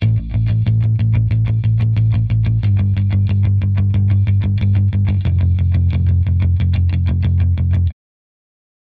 Der Bass klackert gar furchtbar garstig
Hab ich auch mal probiert (so zwischen 2-5 kHz ein wenig die Klackerfrequenz komprimiert und etwas zurückgenommen) und in meine Standarbasskette eingepflegt.